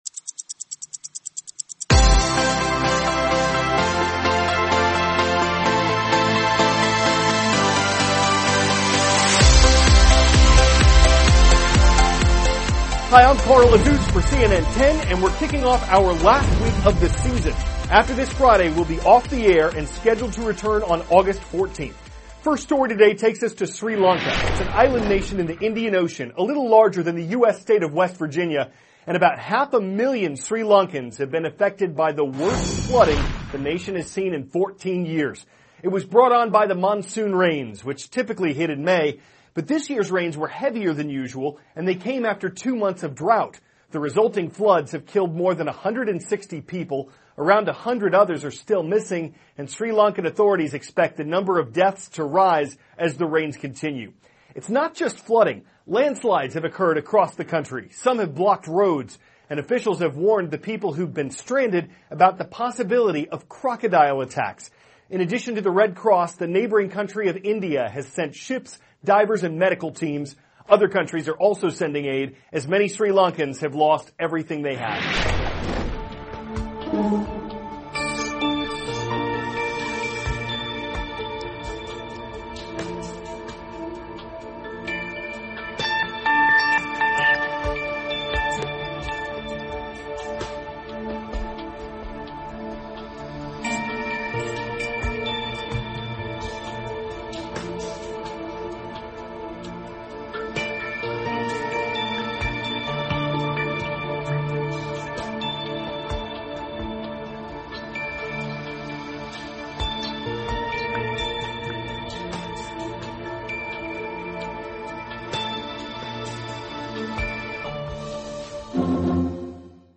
*** CARL AZUZ, cnn 10 ANCHOR: Hi.